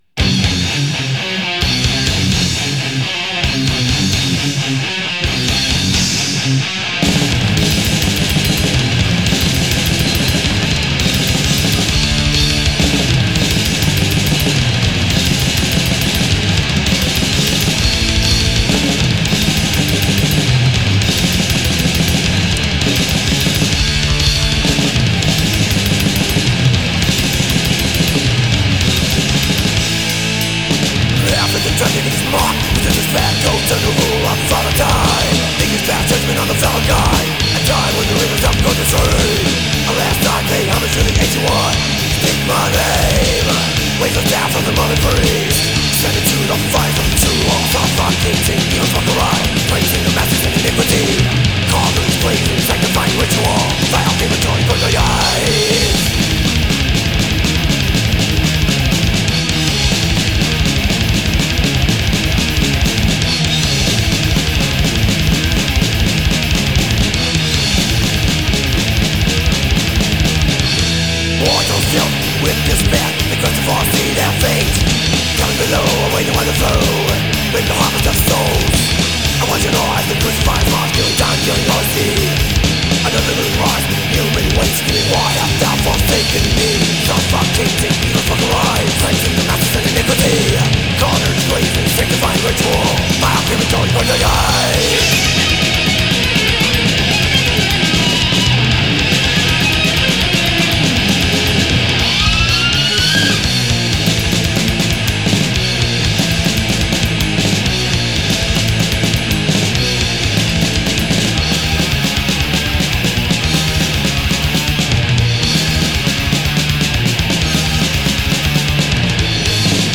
genre: old school death metal album